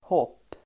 Uttal: [håp:]